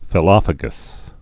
(fĭ-lŏfə-gəs)